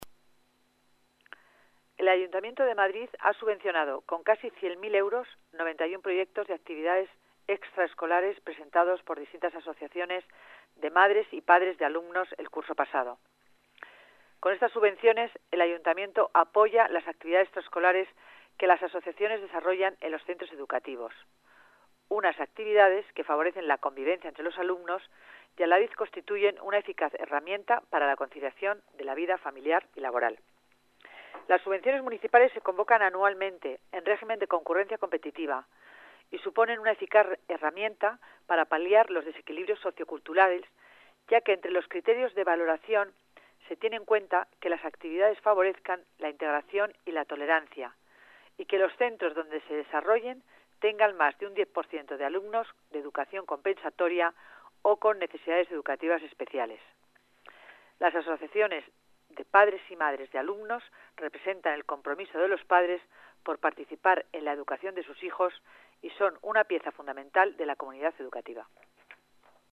Nueva ventana:Declaraciones de la delegada de Familia y Servicios Sociales, Concepción Dancausa, sobre las subvenciones para actividades extraescolares